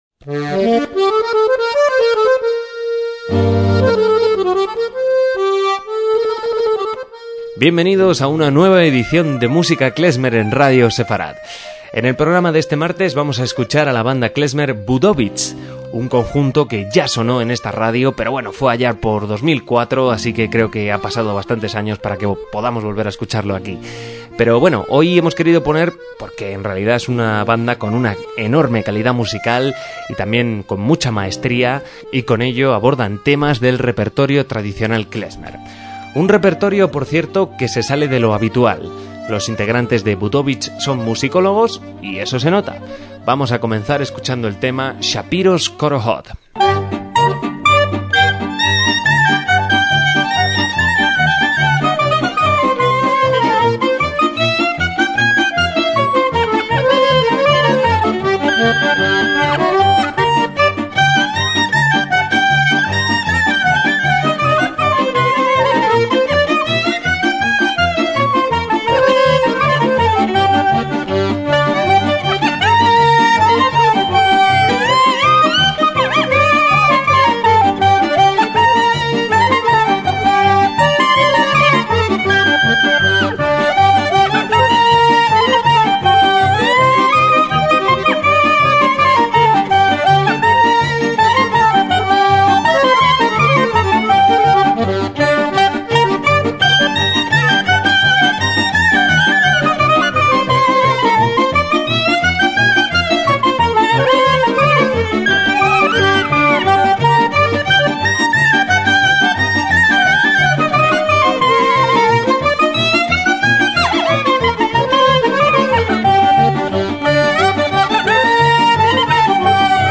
MÚSICA KLEZMER
instrumentos del siglo XIX
tsimbl o cimbalom